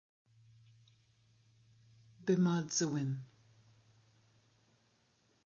pronunciation) – Tsi' niyóht tsi tyúnhe' (